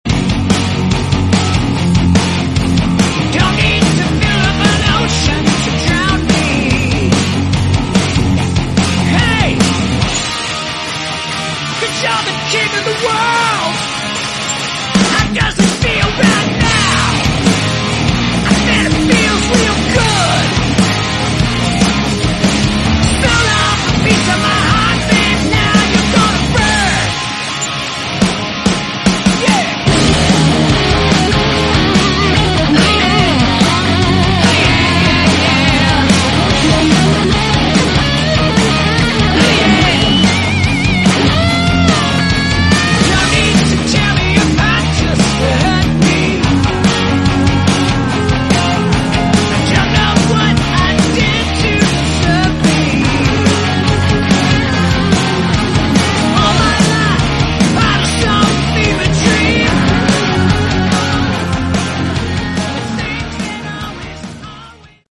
Category: Rock
vocals, guitar, bass
bass guitar